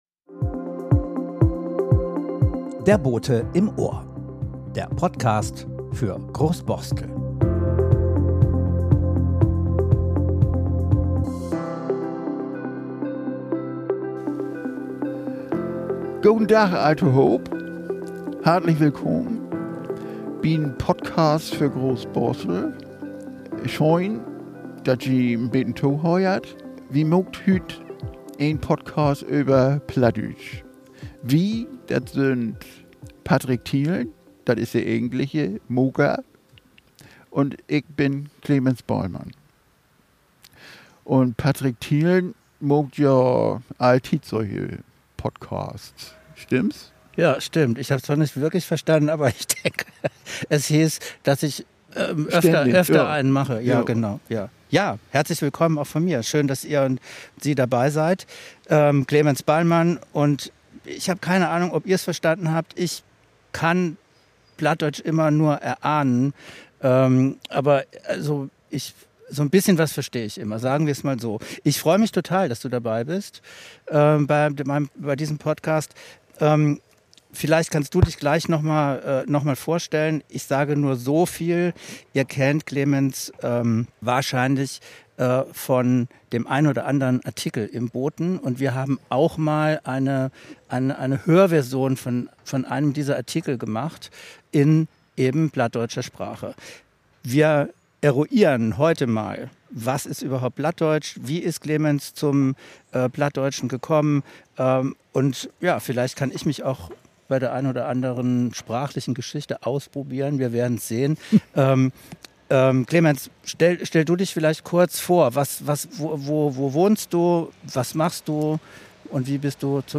Auf einer Groß Borsteler Parkbank sprechen wir darüber, was das für sein Leben bedeutet? Wir gehen zurück in die Zeiten der Hanse. Klären auf, was der Unterschied zwischen Geest - und Marschplatt ist.